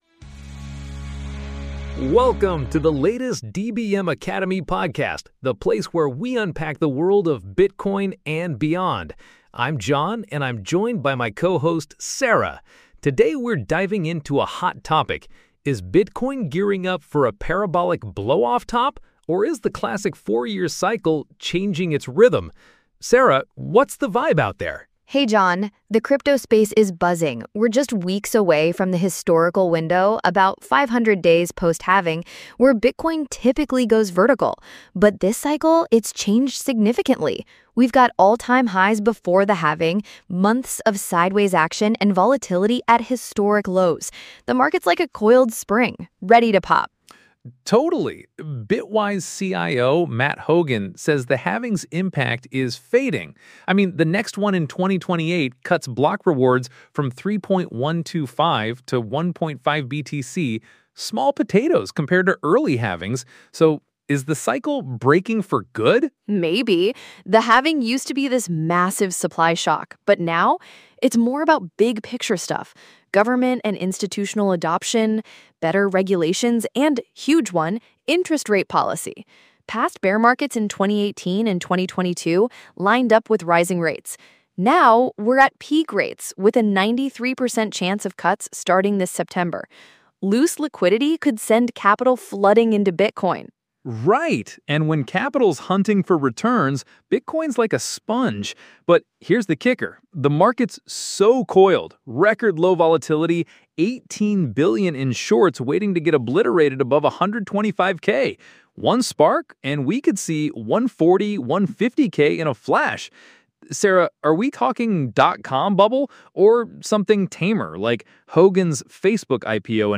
The hosts debate the diminishing impact of halvings versus macro factors, potential market manias, and the likelihood of sharp price movements versus a slow, relentless climb.